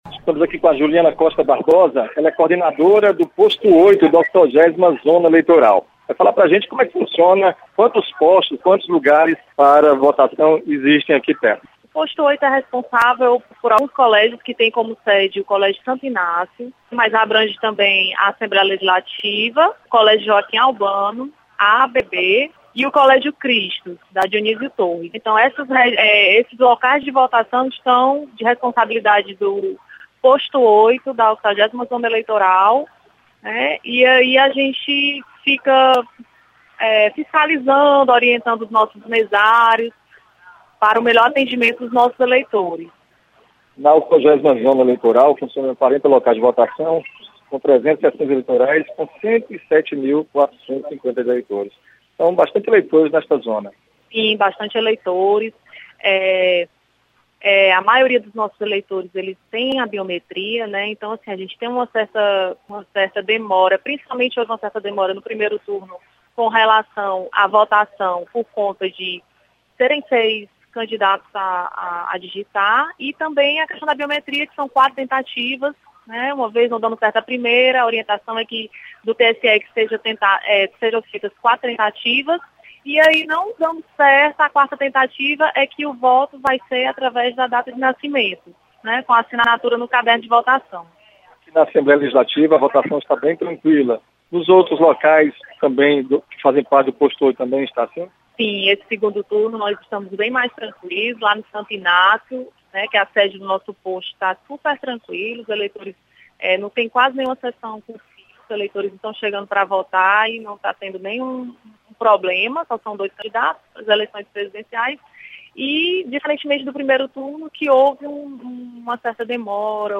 Movimentação de leitores em zona eleitoral no bairro Dionísio Torres. Repórter